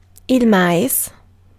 Ääntäminen
IPA : /kɔːn/
IPA : /kɔɹn/